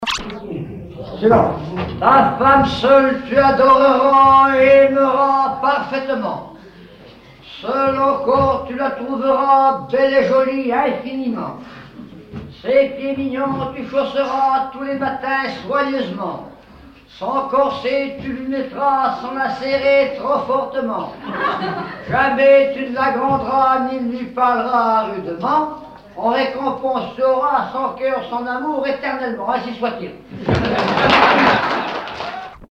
Repas du soir.
Usage d'après l'analyste circonstance : fiançaille, noce ;
Genre strophique
Catégorie Pièce musicale inédite